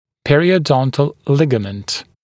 [ˌperɪəu’dɔntl ‘lɪgəmənt][ˌпэриоу’донтл ‘лигэмэнт]зубодесневая связка, периодонтальная связка